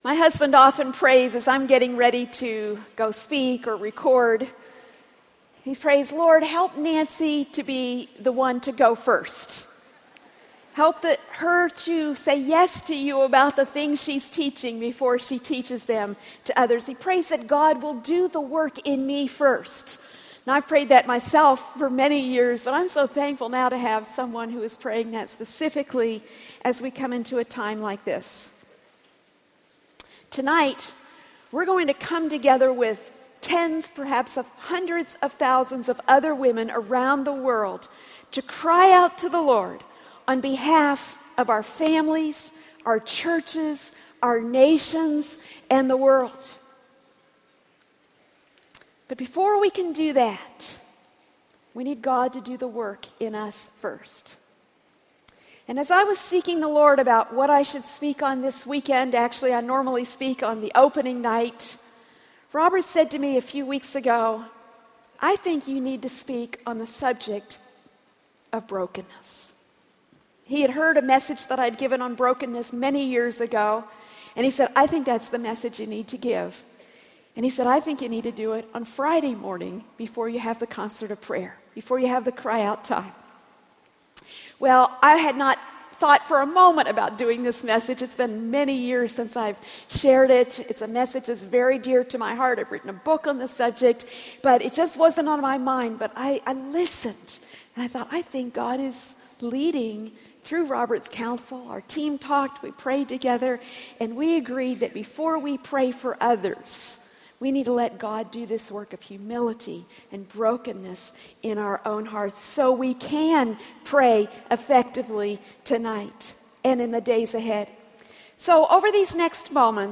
Crying Out from a Heart of Brokenness | True Woman '16 | Events | Revive Our Hearts